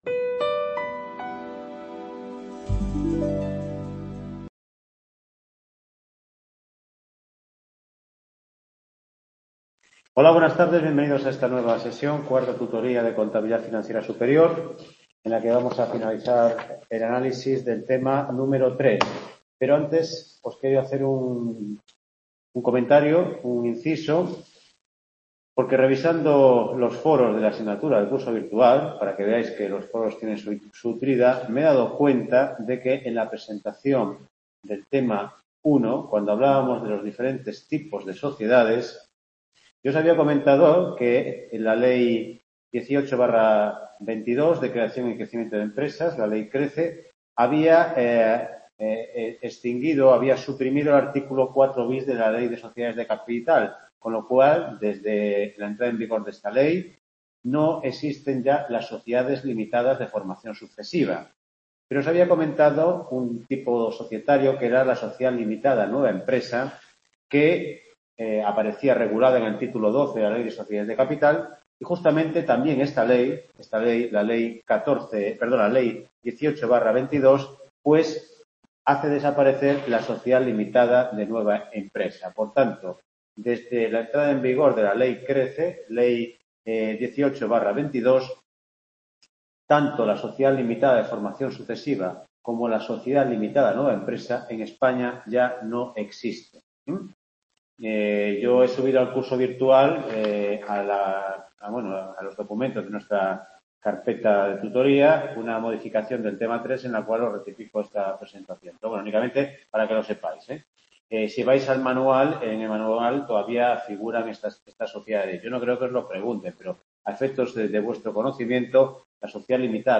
TUTORIA 4